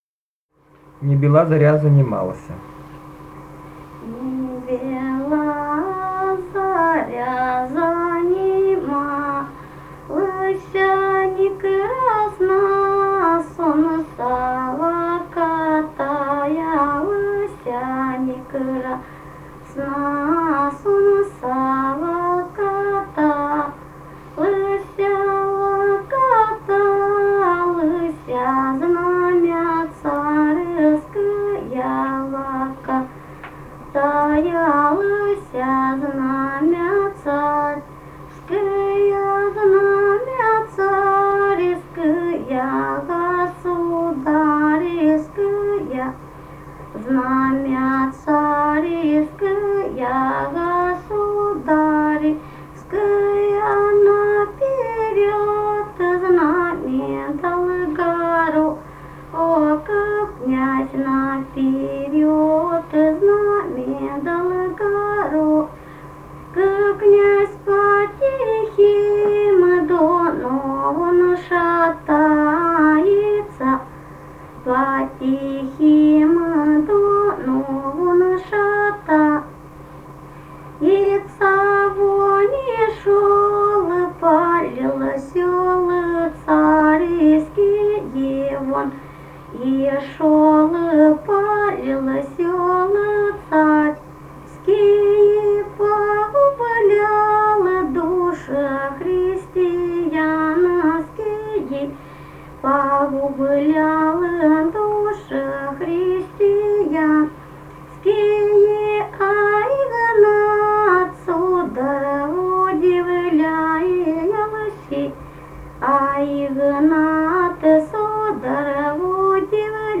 «Не бела заря занималася» (историческая «об Игнате»).
Ставропольский край, с. Левокумское Левокумского района, 1963 г. И0724-14